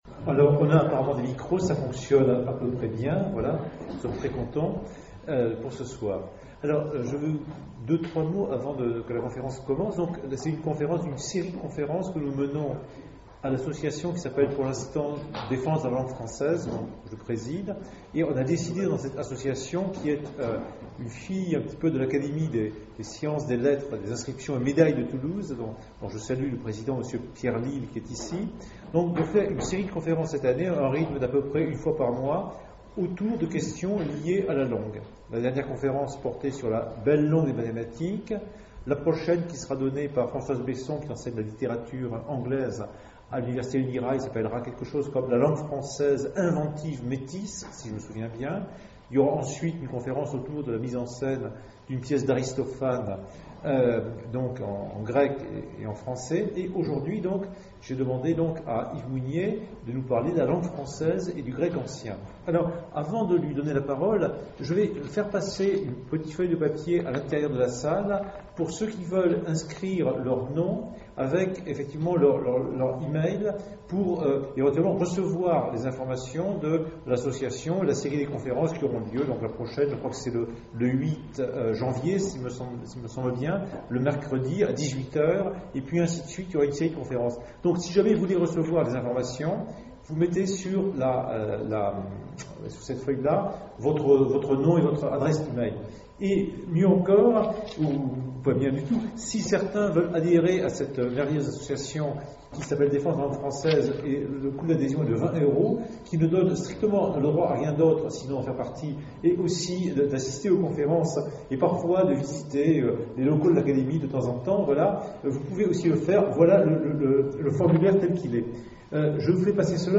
Présentation